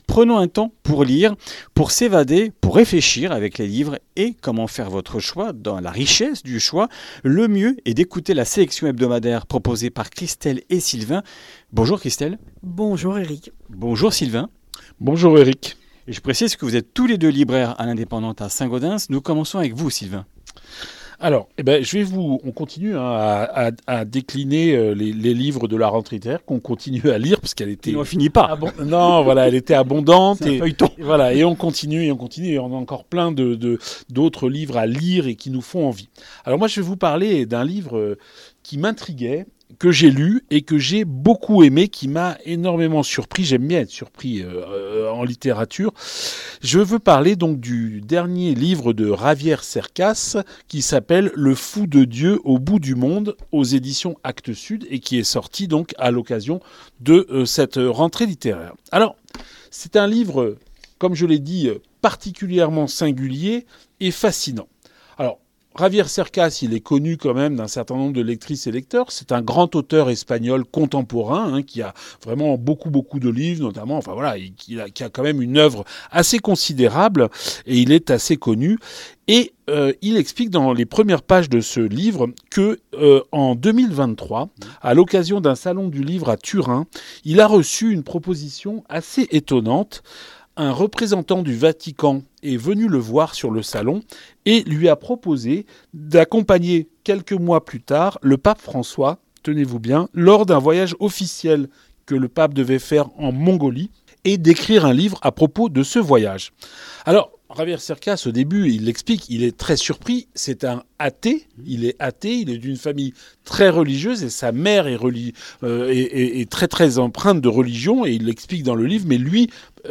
Comminges Interviews du 07 nov.